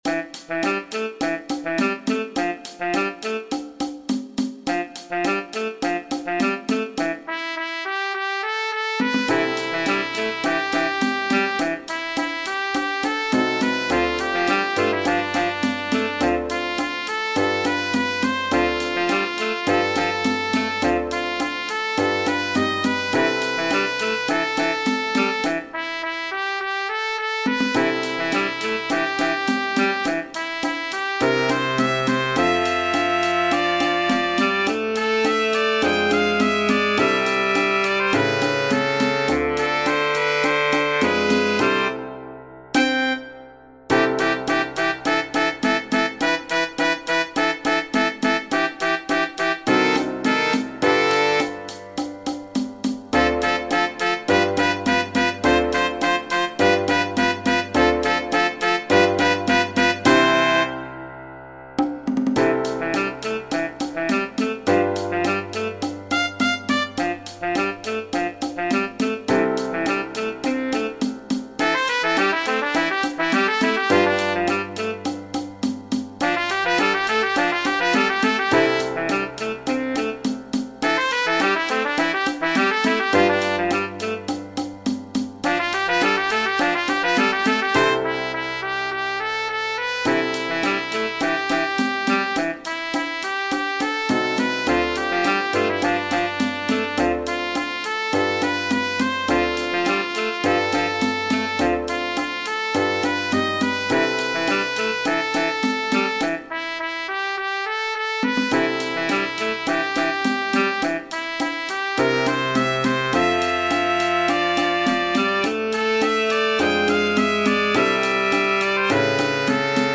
Genere: Moderne
un brano a ritmo di cha cha cha